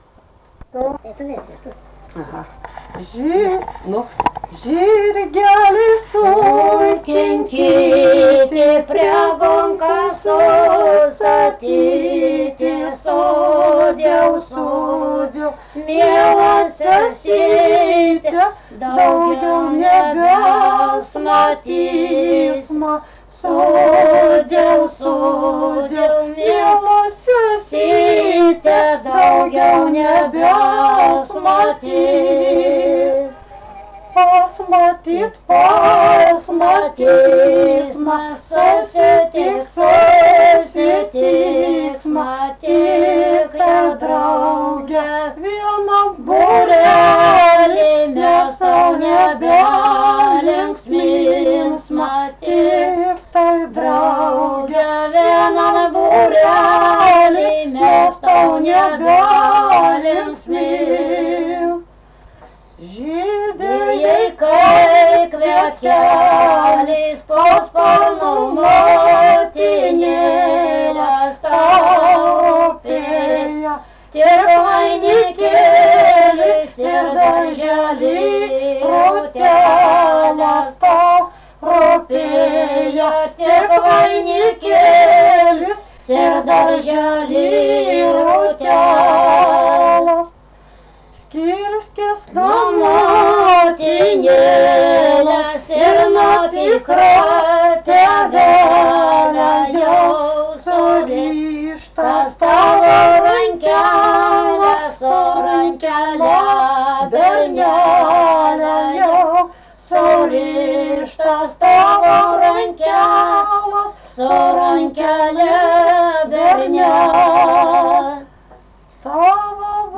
Dalykas, tema daina
Erdvinė aprėptis Rageliai
Atlikimo pubūdis vokalinis